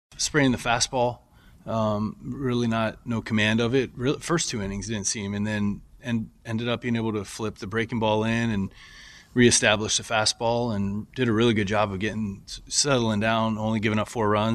Manager Donnie Kelly says it took a while for starter Johan Oviedo to settle in after a rough first inning.